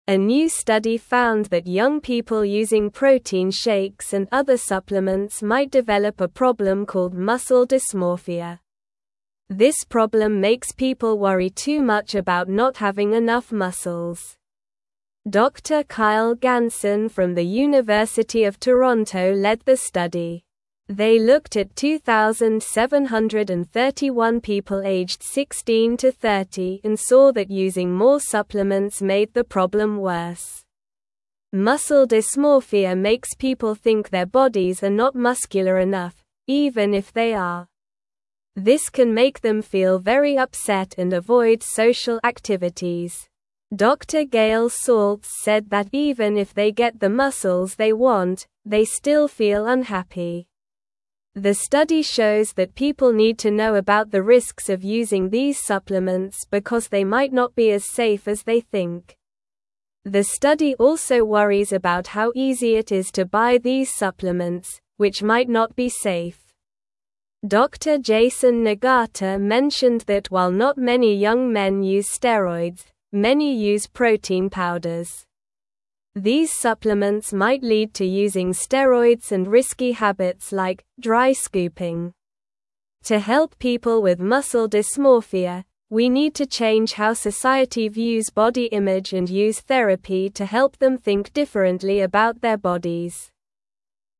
Slow
English-Newsroom-Lower-Intermediate-SLOW-Reading-Worrying-About-Muscles-Can-Make-You-Unhappy.mp3